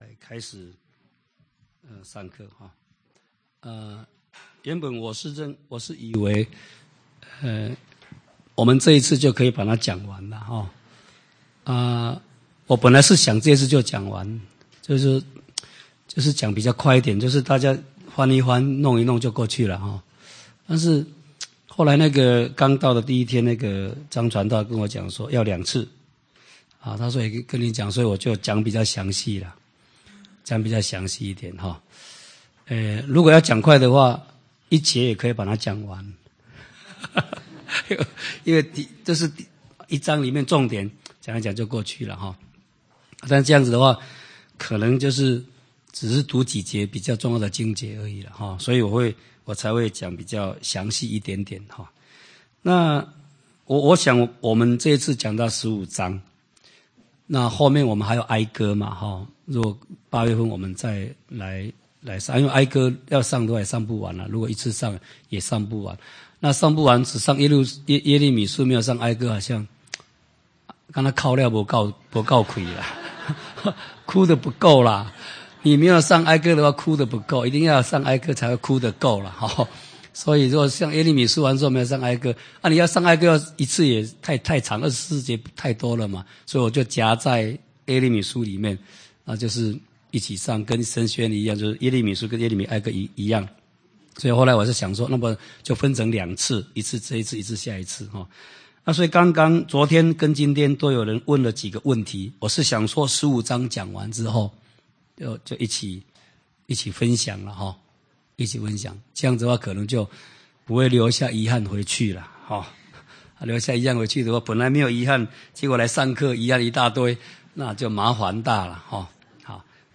講習會